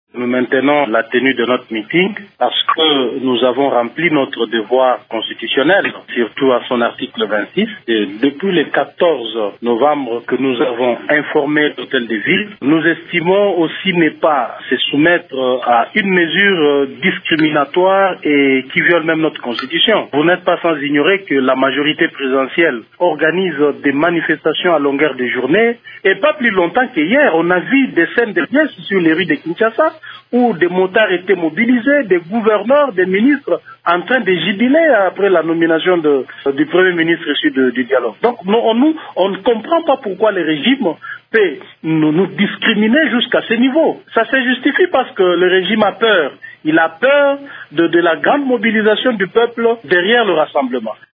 Dans cet extrait sonore, Jean Marc Kabund soutient que le parti au pouvoir organise sans inquiétude des manifestations dont celle de jeudi en rapport avec la nomination du nouveau Premier ministre: